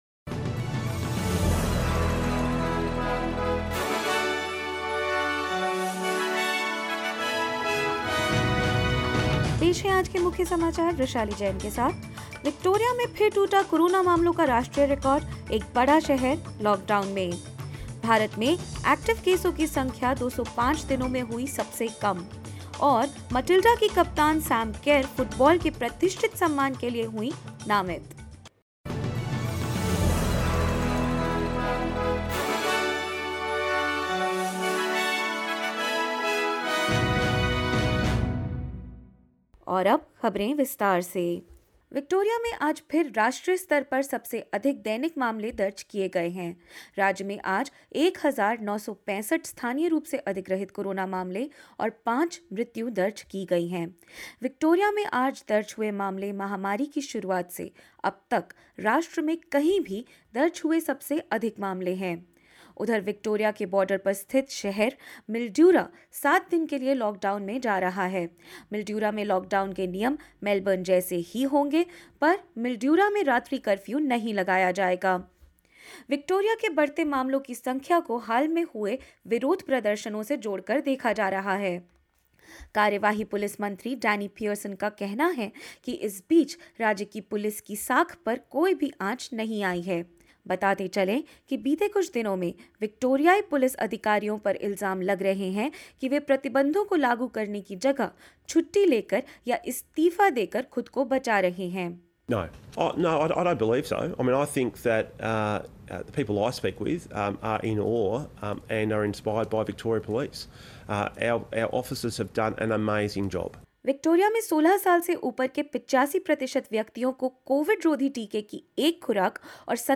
In this latest SBS Hindi News bulletin of Australia and India: Victoria records the highest ever daily tally for an area in Australia of 1,965 coronavirus cases; India registers the lowest number of active cases in 205 days and more.